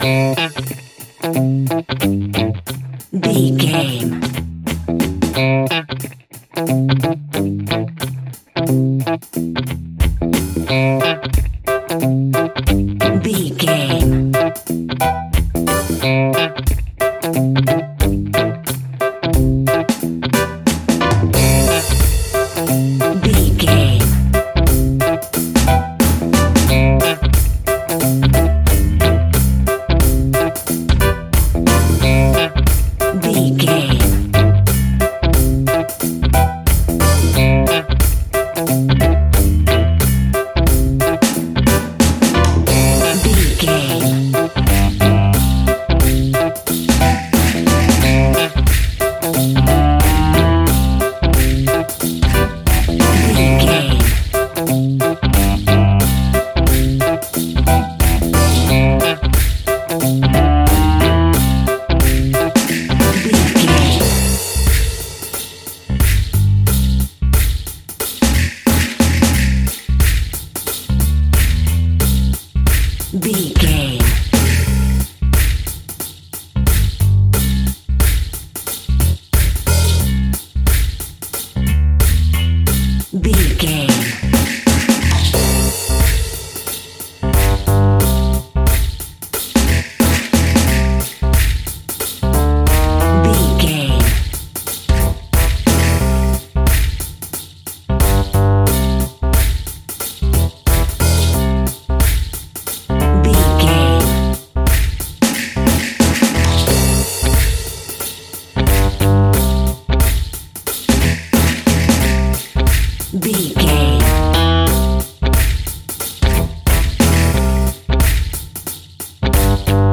Take me back to the old skool retro seventies reggae sounds!
Ionian/Major
reggae instrumentals
laid back
chilled
off beat
drums
skank guitar
hammond organ
percussion
horns